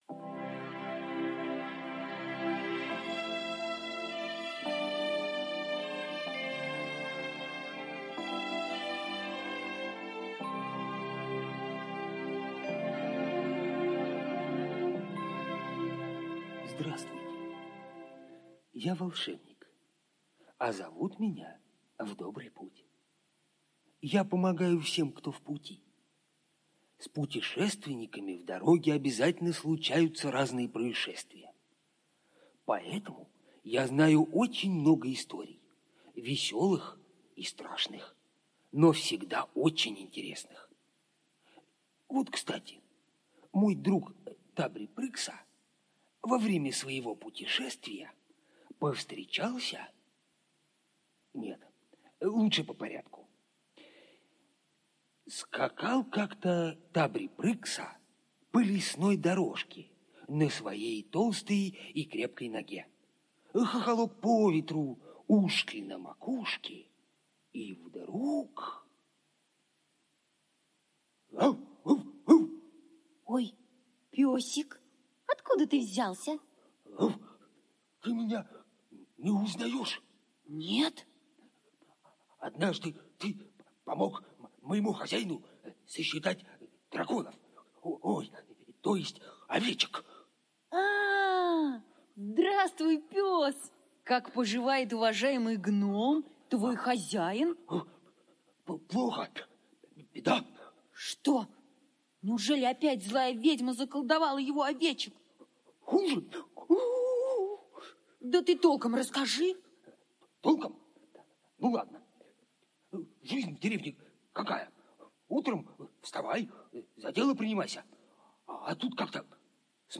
В стране Домашних Животных - аудиосказка Бахаревой - слушать онлайн
В стране Домашних Животных – аудиосказка Бахаревой Г. Музыкальная сказка о необычной жизни домашних животных.